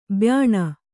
♪ byāṇa